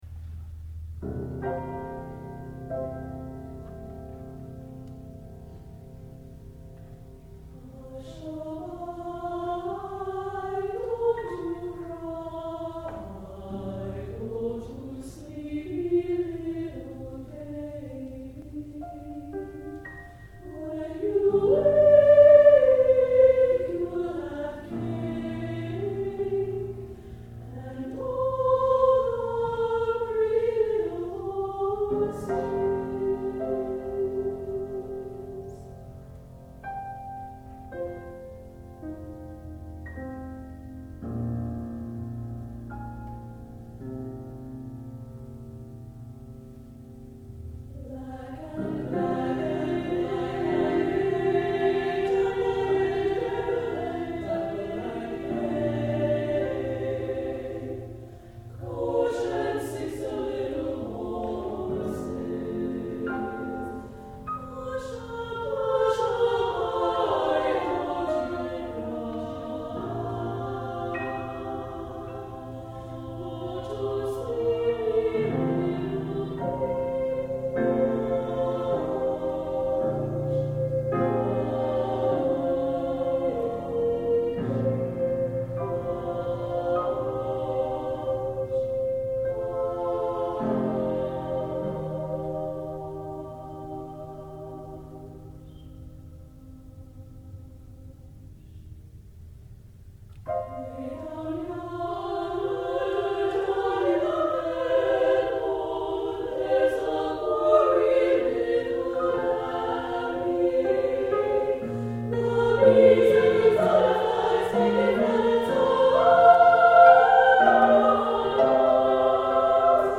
SSA choir and piano